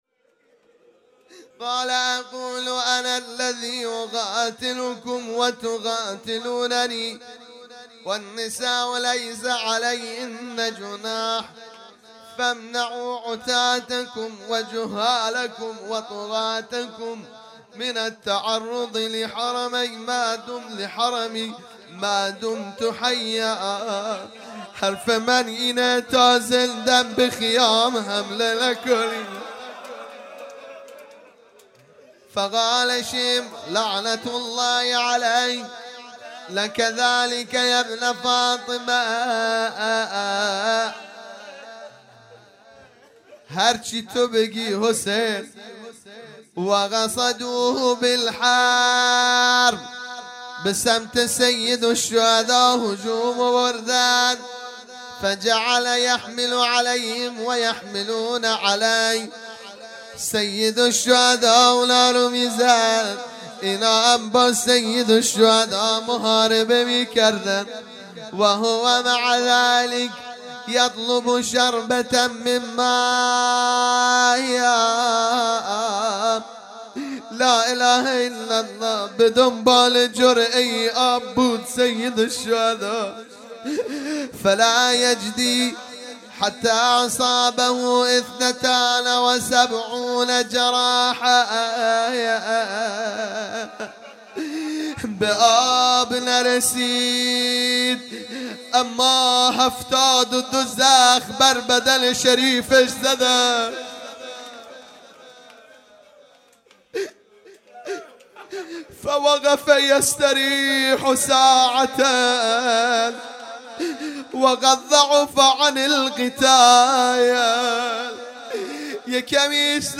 صوت های مراسم ظهر عاشورا محرم الحرام 95
مقتل خوانی بخش سوم